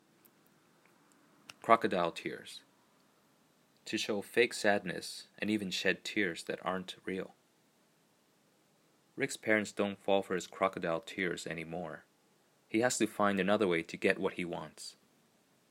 英語ネイティブによる発音は下記のリンクをクリックしてください。
crocodiletears.mp3